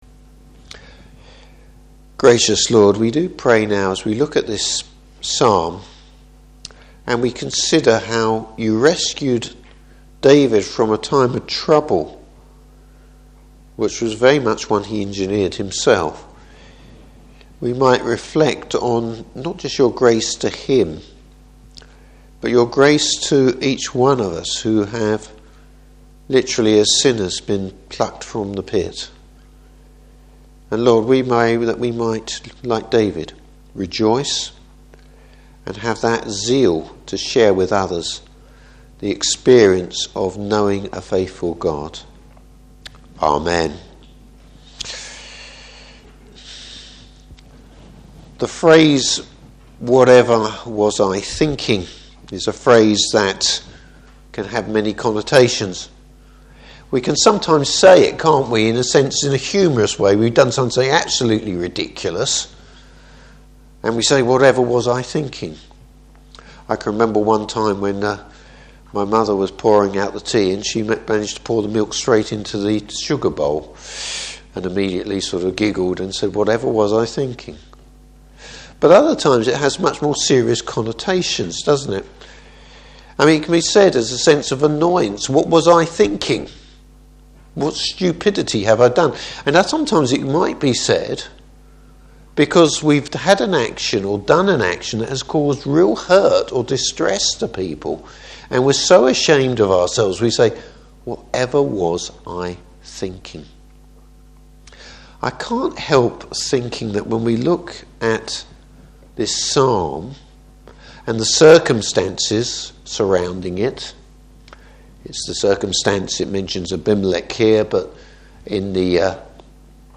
Service Type: Evening Service A song about David’s failure, but the Lord’s deliverance.